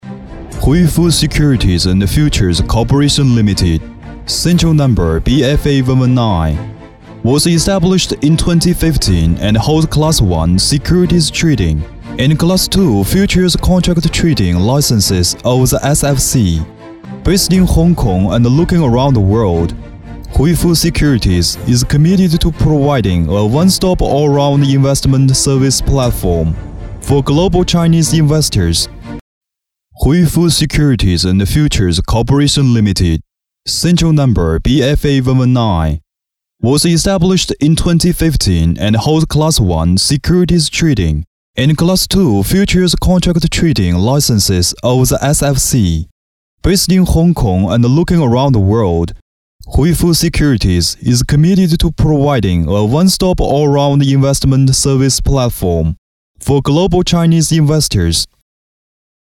宣传片-大气浑厚